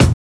TOP THUD.wav